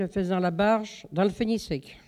Localisation Bois-de-Céné
Catégorie Locution